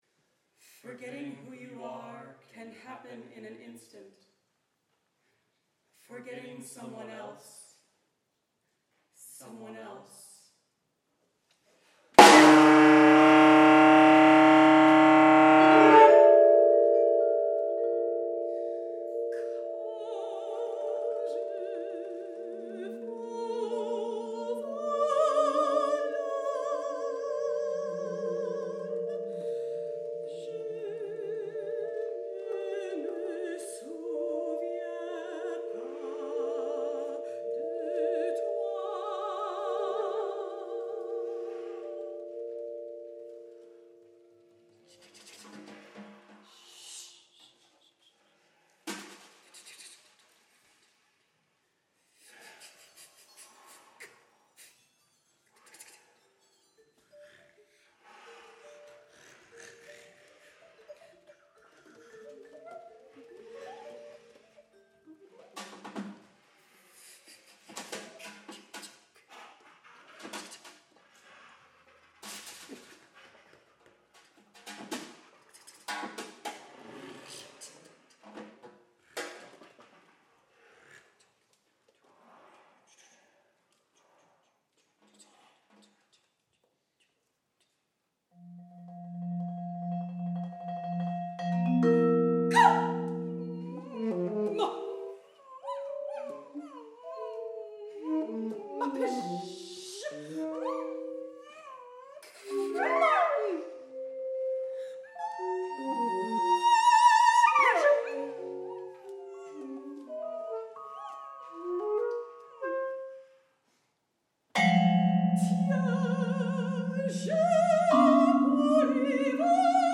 for soprano voice, tenor saxophone, percussion